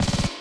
59LOOP SD4-L.wav